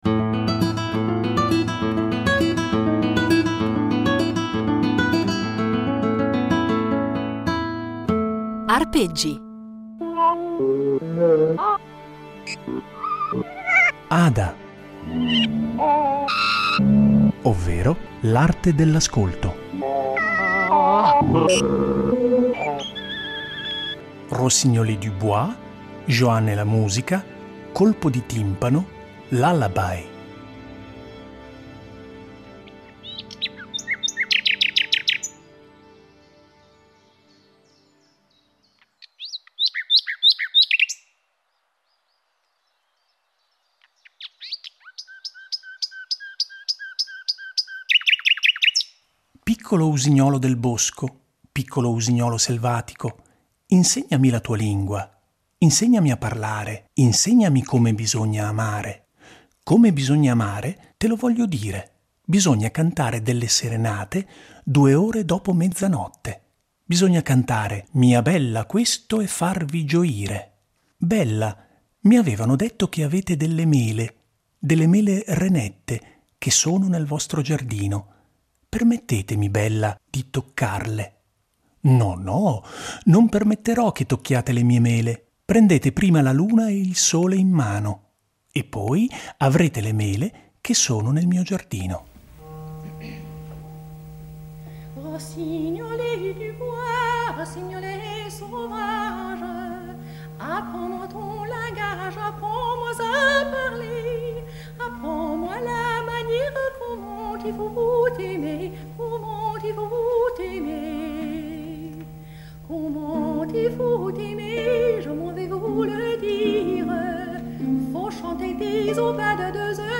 un patchwork fatto di musiche, suoni, rumori e letture